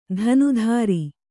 ♪ dhanu dhāri